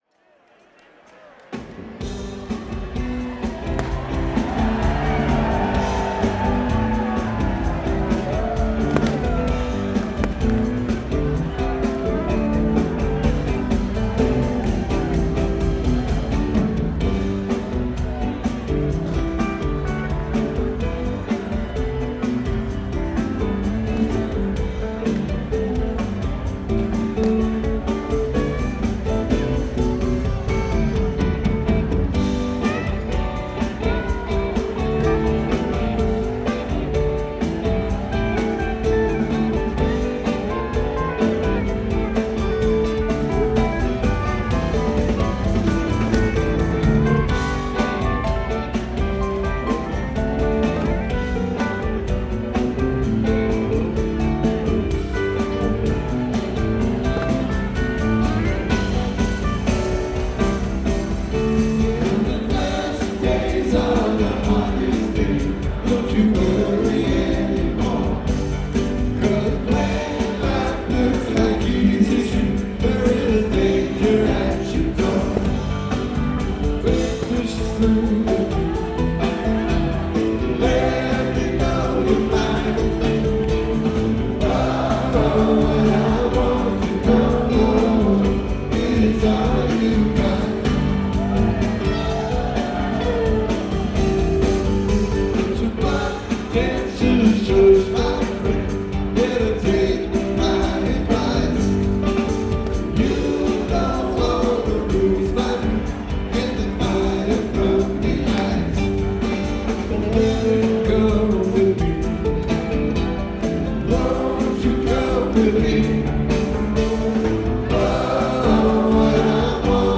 Capitol Theatre Port Chester NY
New Years Eve
bass
drums
guitar
keys